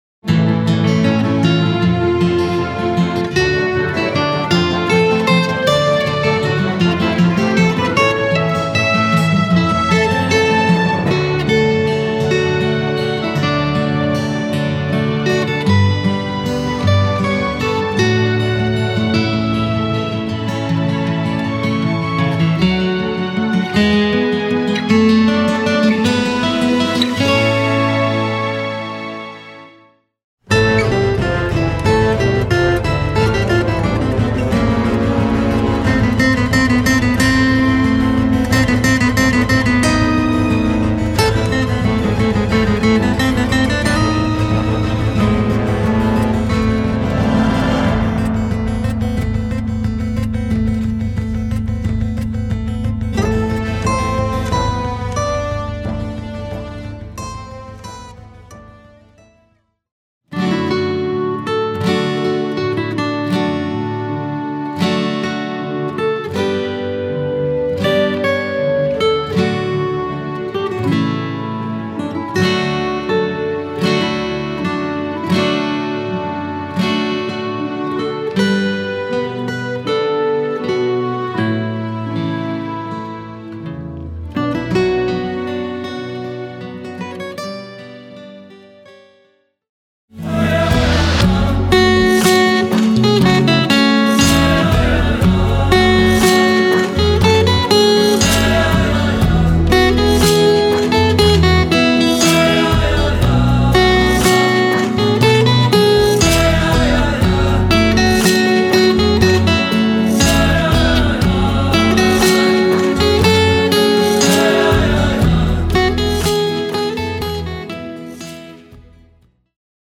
Voicing: Guitar/DVD